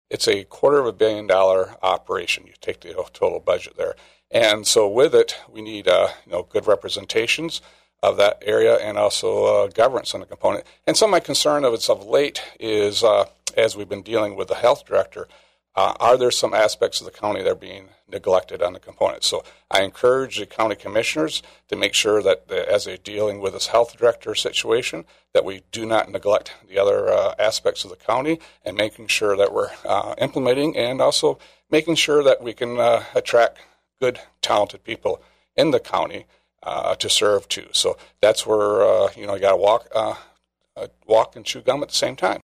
It was another example of what some may say has been poor management of county affairs since the current board was installed on January 3rd. State Senate Minority Whip Roger Victory, a second-term Republican from Hudsonville, voiced some concerns.